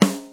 share-drum.wav